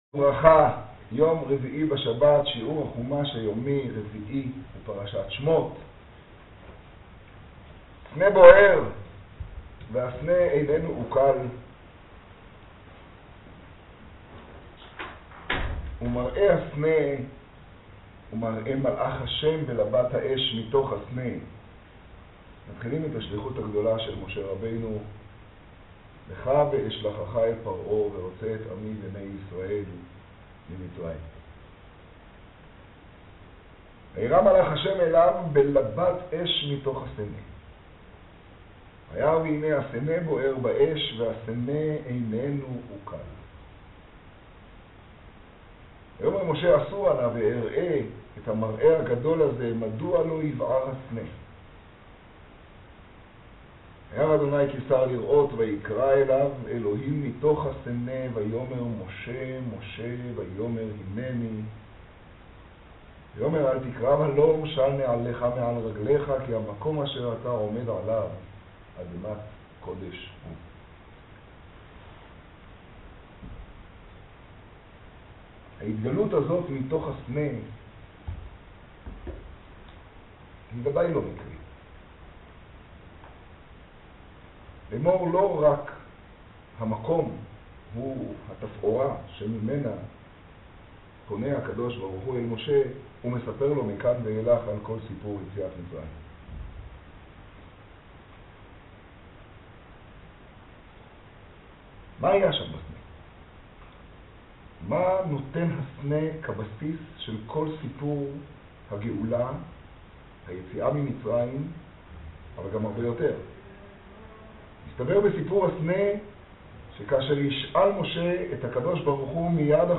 לימוד פרשת שמות תשעה. רביעי של הפרשה.